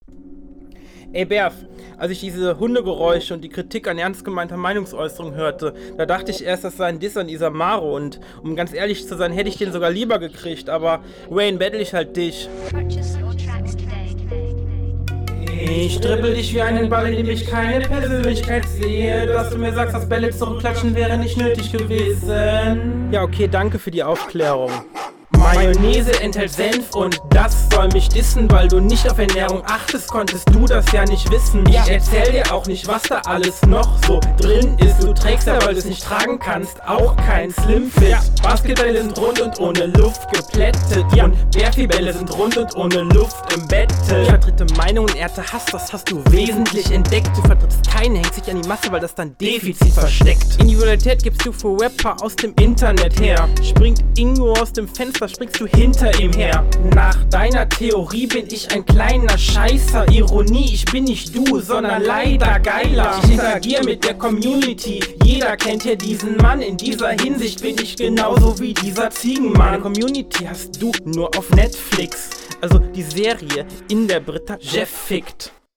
Flow: Er stamelt eher und sich wie sprechen an wie wenn der lehrer ein gedicht …
Flow: definitiv unterlegen Text: nichts dabei was mich packt Soundqualität: unsynchron, tut weh in den …